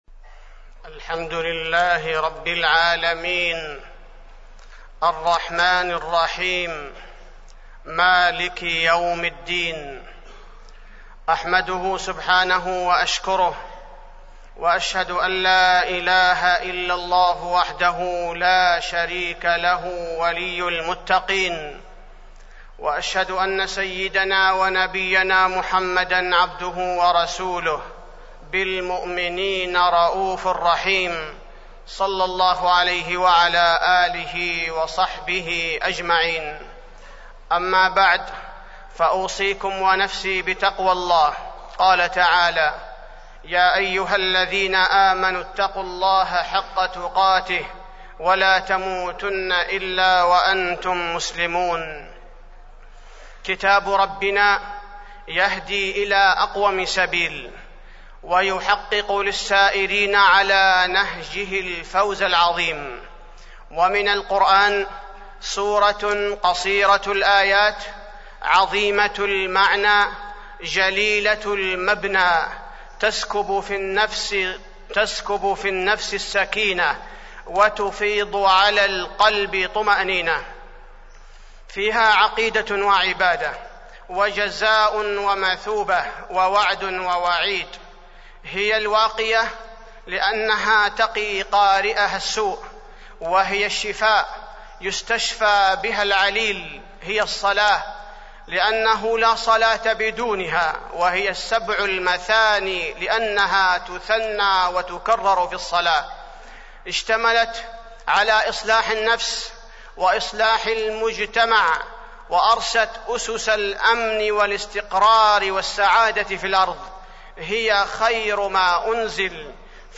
تاريخ النشر ١ صفر ١٤٢٦ هـ المكان: المسجد النبوي الشيخ: فضيلة الشيخ عبدالباري الثبيتي فضيلة الشيخ عبدالباري الثبيتي تفسير سورة الفاتحة